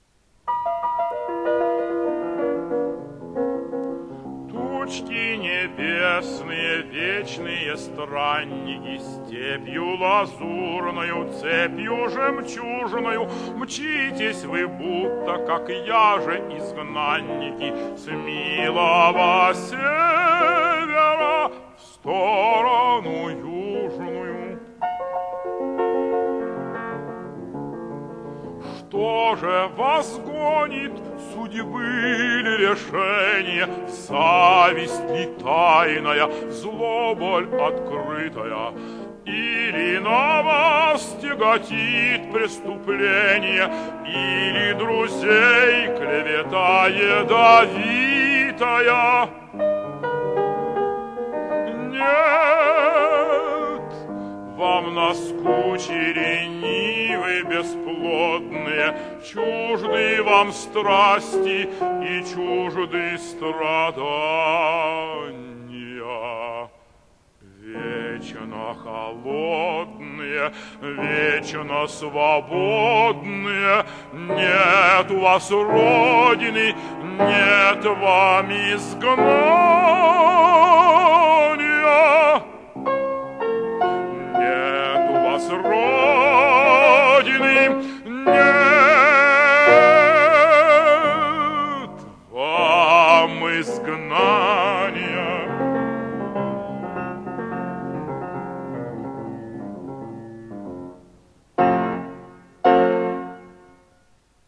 Певцы
Режим: Stereo